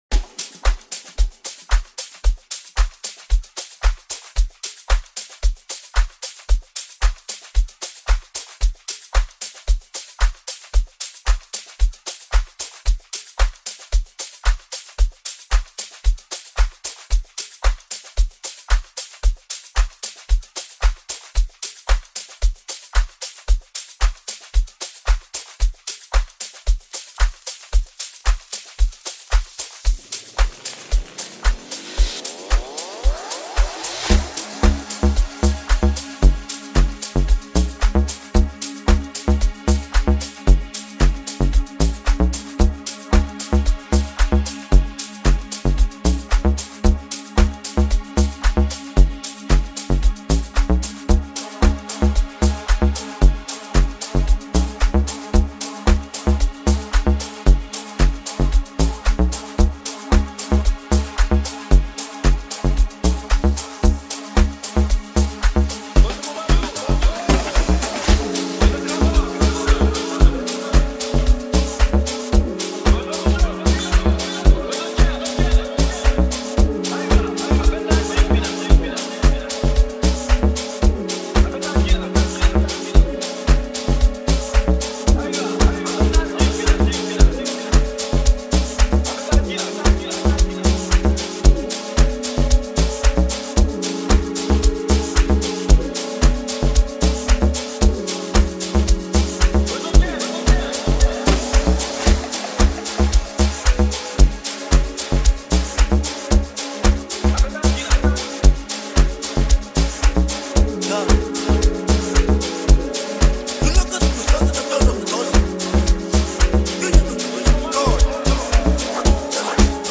Home » Amapiano » DJ Mix » Lekompo
South African singer-songsmith